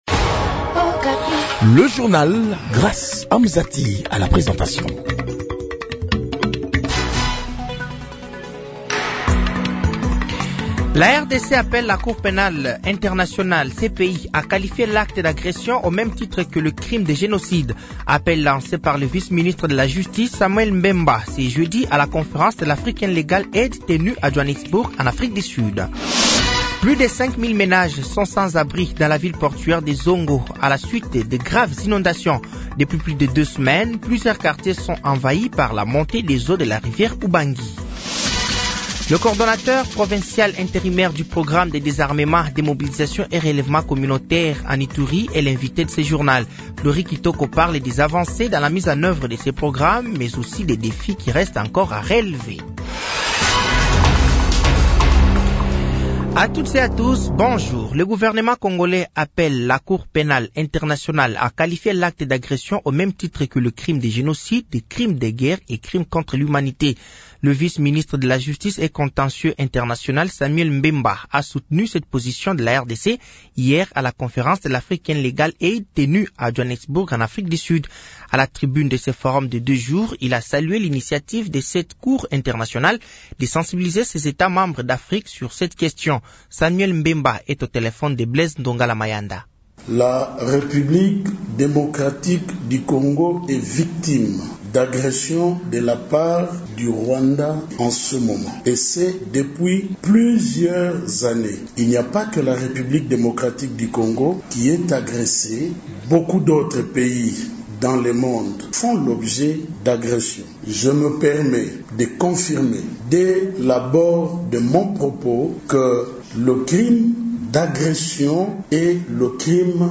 Journal français de 8h de ce vendredi 1er novembre 2024